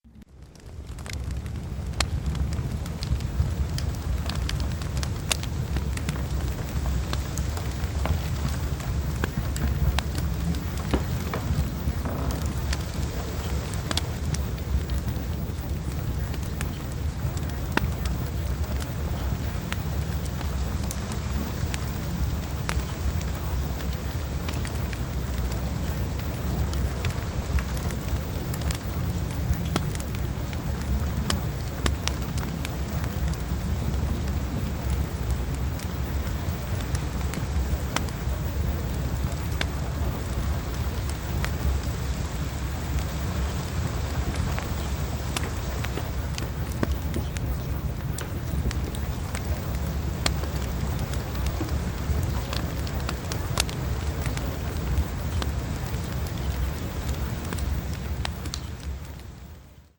PROGRAMMING-GENIUS-Fireplace-Sample.mp3